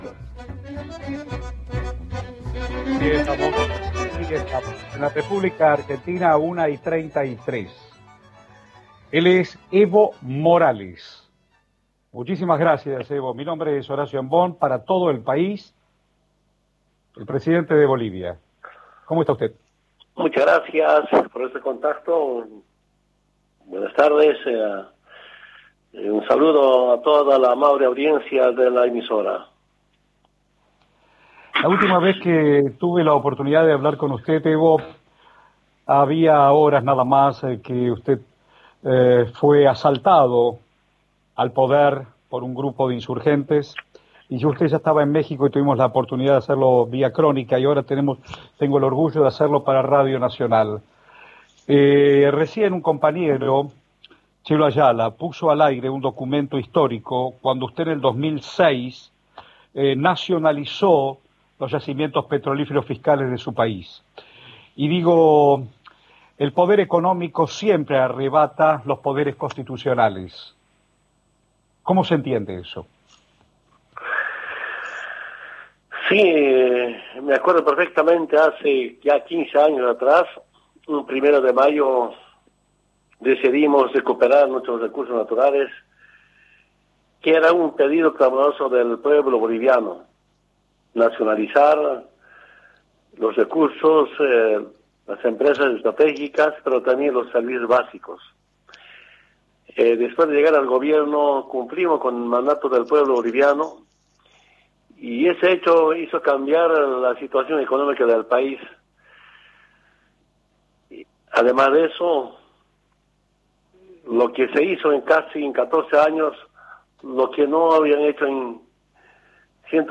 El presidente derrocado de Bolivia, Evo Morales, habló en exclusiva por Radio Nacional.